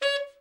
TENOR SN  30.wav